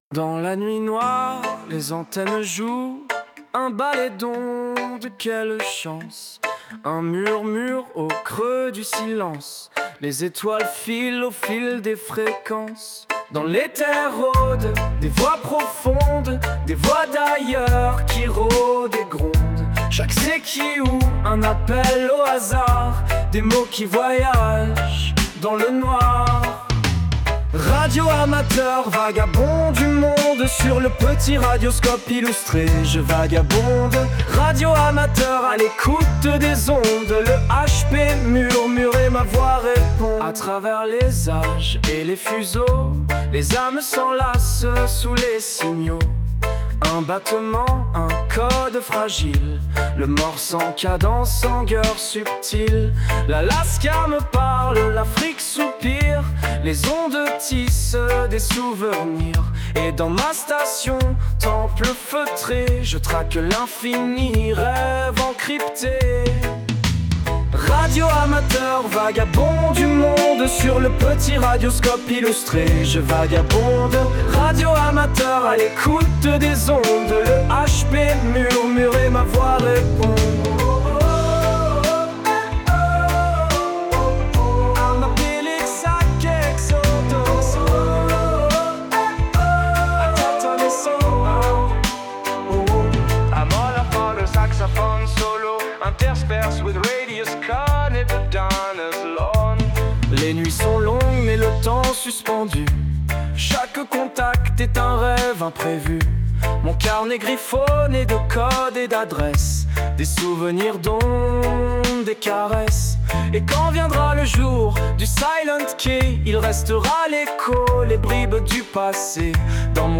Wersja francuska: